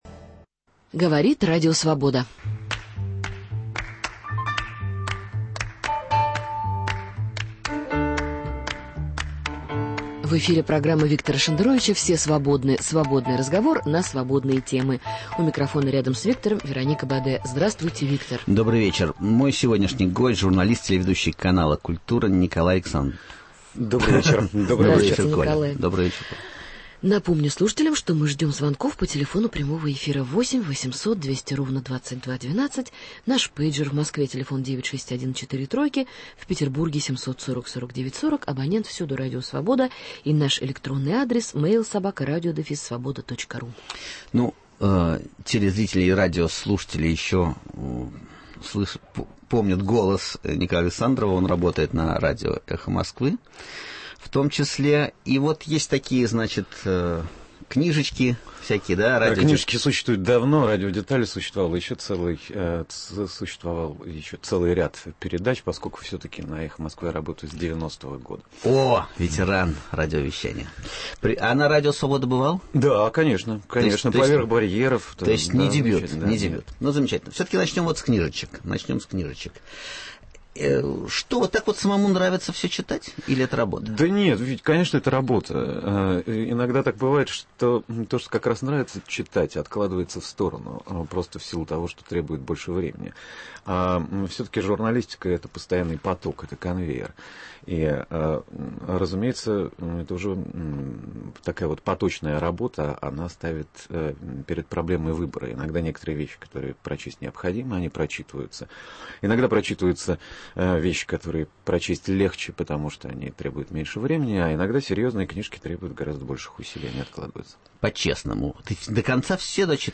Свободный разговор на свободные темы. Гостей принимает Виктор Шендерович, который заверяет, что готов отвечать на любые вопросы слушателей, кроме двух: когда он, наконец, уедет в Израиль и сколько он получает от ЦРУ?